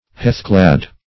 Heathclad \Heath"clad`\ (-kl[a^]d`), a. Clad or crowned with heath.